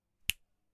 描述：一个丰盛的手指点击
Tag: 点击 按扣 手指 手指弹响 fingersnap 点击